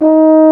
Index of /90_sSampleCDs/Roland L-CD702/VOL-2/BRS_Baritone Hrn/BRS_Euphonium
BRS BARI D#3.wav